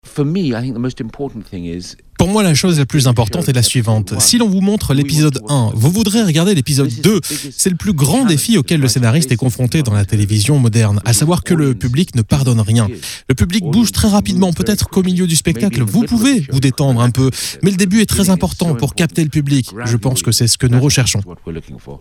Mais quelle que soit la durée, une même exigence demeure : être captivant pour le public, explique l'écrivain britannique Anthony Horowitz (Alex Rider, Le Pouvoir des cinq, Les Frères Diamant...), président du jury.